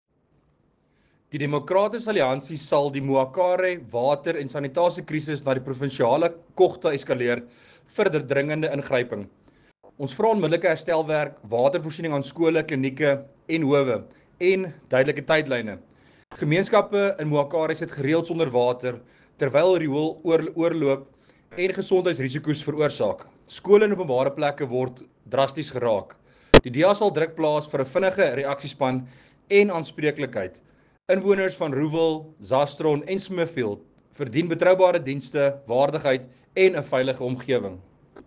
Afrikaans soundbite by Werner Pretorius MPL and